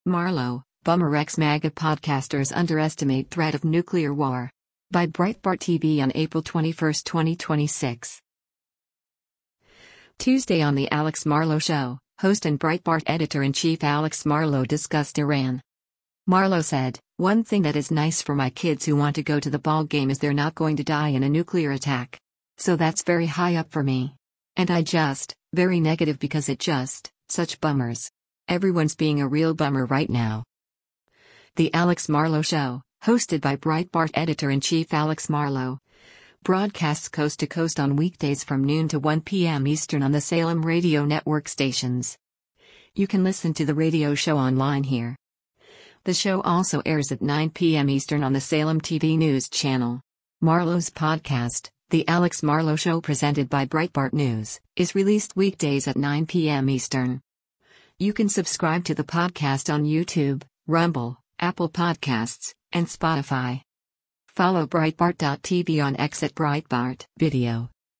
Tuesday on “The Alex Marlow Show,” host and Breitbart Editor-in-Chief Alex Marlow discussed Iran.